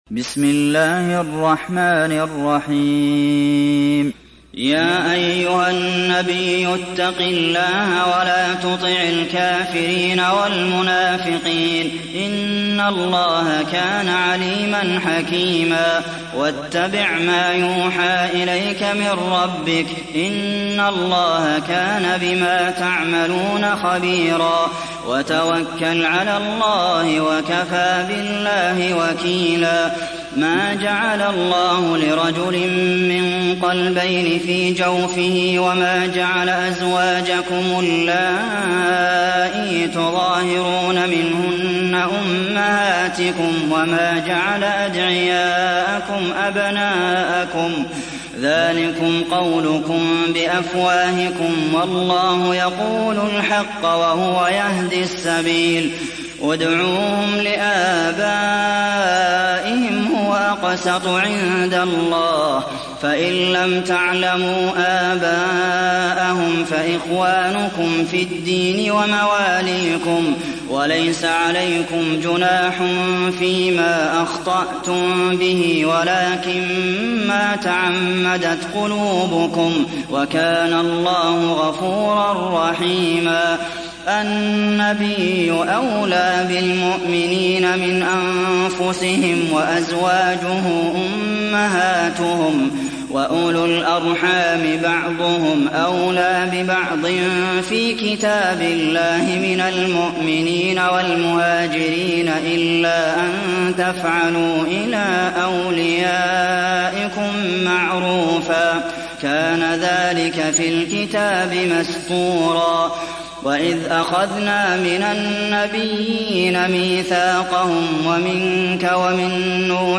সূরা আল-আহযাব mp3 ডাউনলোড Abdulmohsen Al Qasim (উপন্যাস Hafs)